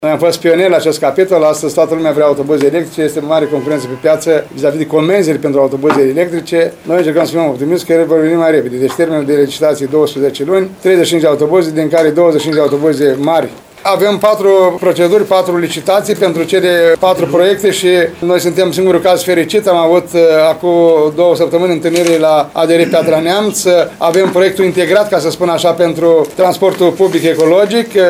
Divizia Electrică TPL își va avea sediul pe strada Căpitan Grigore Andrei, după cum a precizat primarul ION LUNGU.